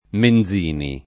[ men z& ni ]